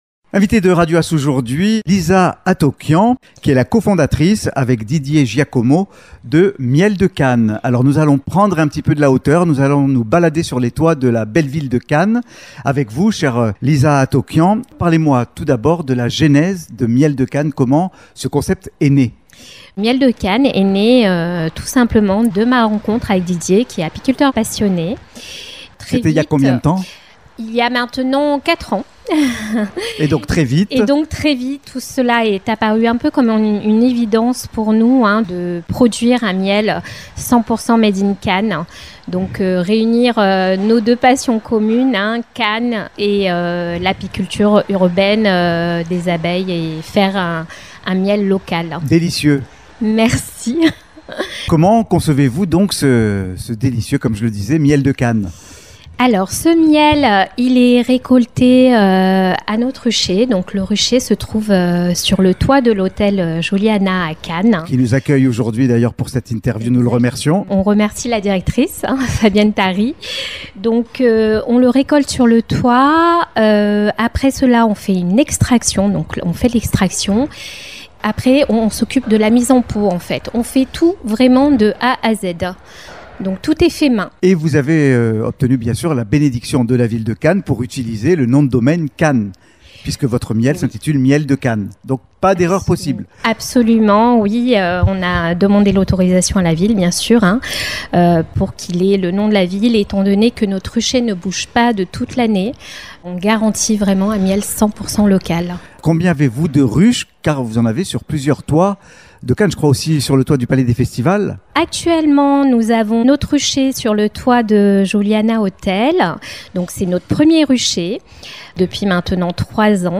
Émission Radio AS | decembre 2025